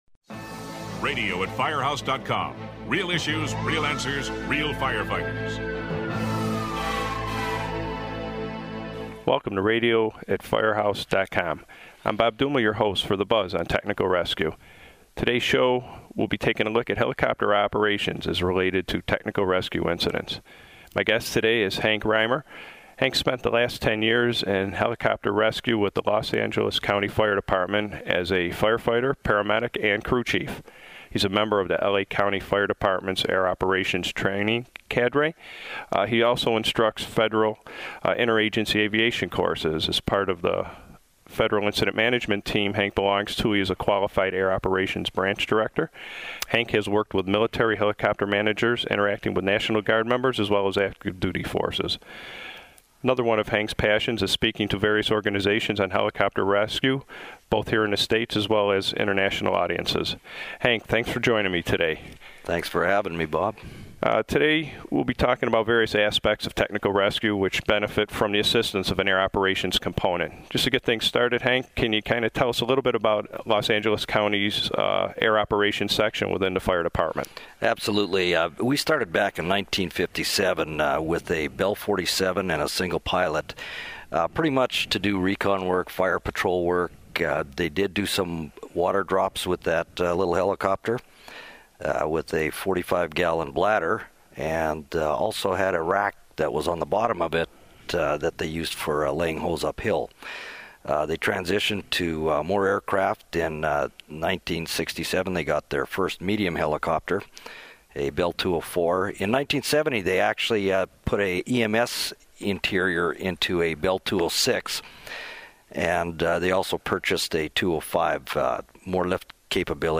This podcast was recorded at Firehouse World in March.